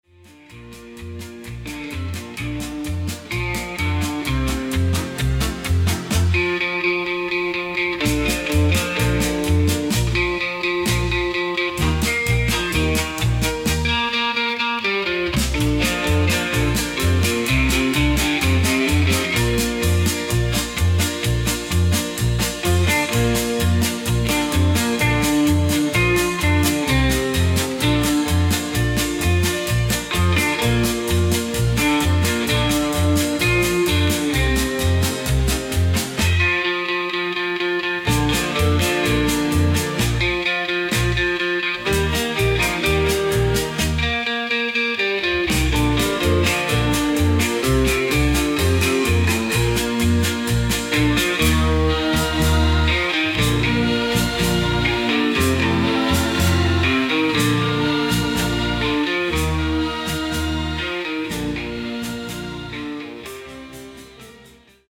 Western Style